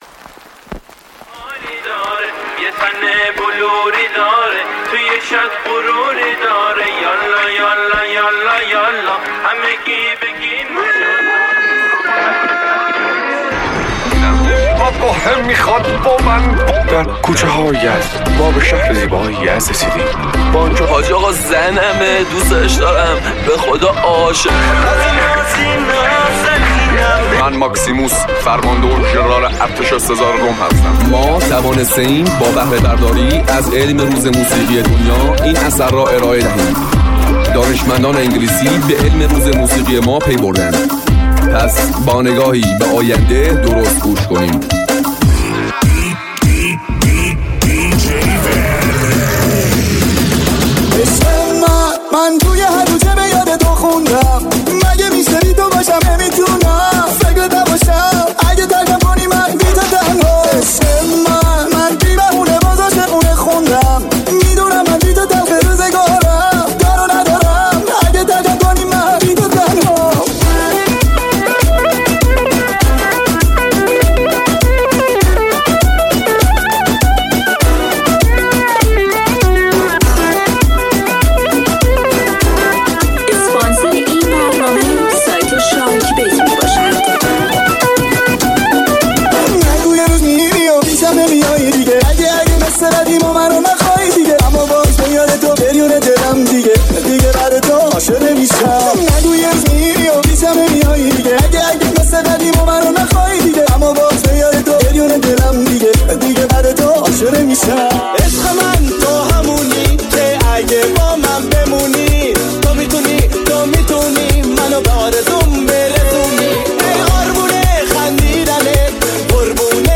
مجموعه ای از بهترین آهنگ های شاد قدیمی به یاد ماندنی